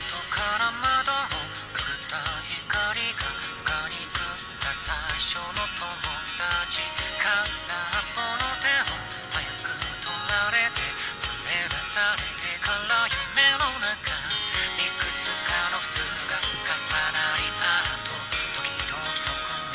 参考までに、ヘッドホンから聞こえるサウンドを録音したデータを掲載する。
▼ヘッドホンにマイクを近接させて録音。
※あくまで低音・高音の強さをなんとなく分かってもらうためのものであり、実際の聴こえ方とは異なるので注意。